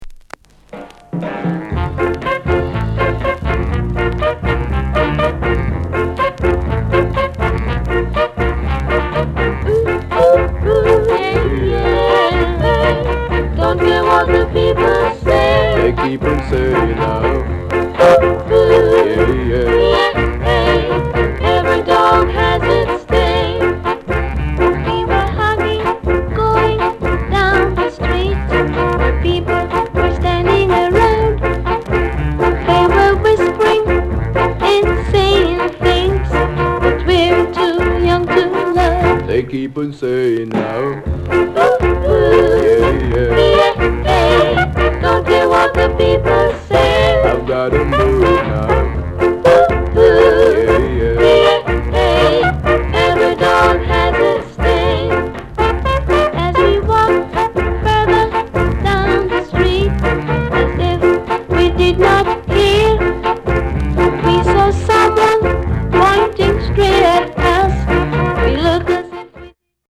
SOUND CONDITION A SIDE VG(OK)
SKA